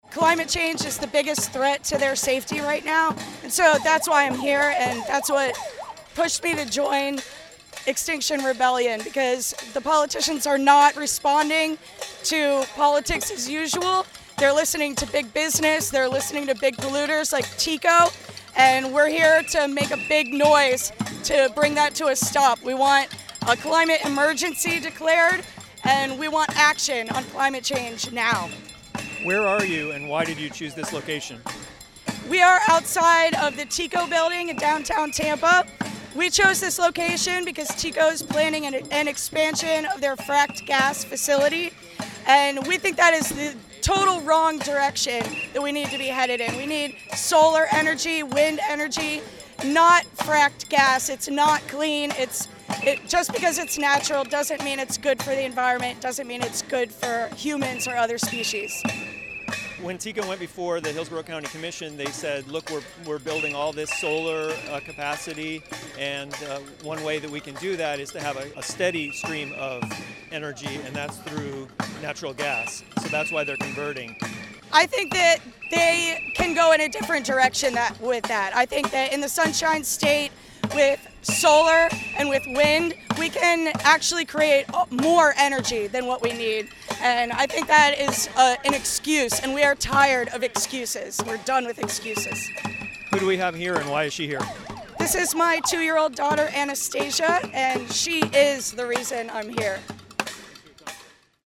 On Thursday morning about fifteen members of Extinction Rebellion held what they called a ‘noise rebellion’ outside the downtown Tampa headquarters of Tampa Electric Company.